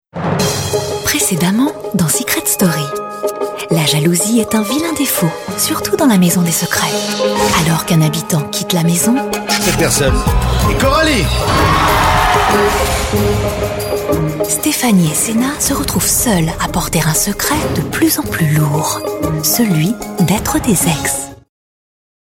Catégories : Emission, Emissions TV, Extraits Audio | Mots-clés : Benjamin Castaldi, Confidentiel, Murmuré, PREVIOUSLY, SECRET STORY, Sensuel, TF1 | Permaliens